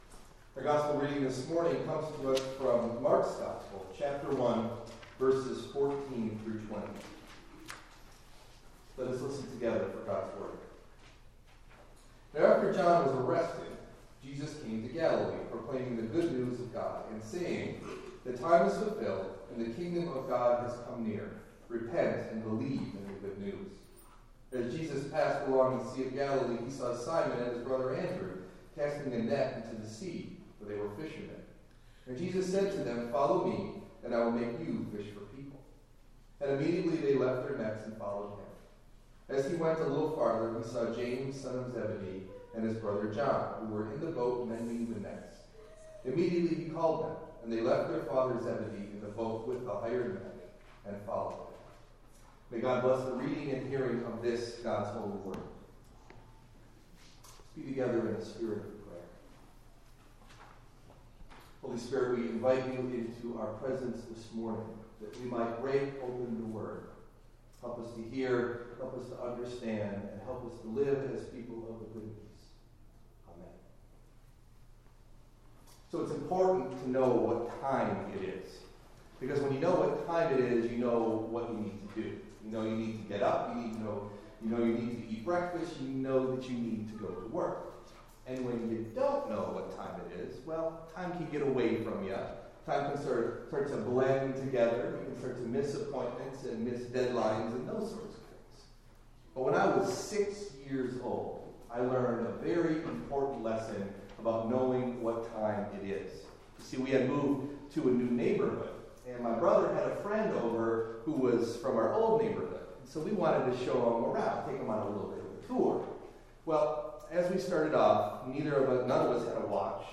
Delivered at: The United Church of Underhill (UCC and UMC)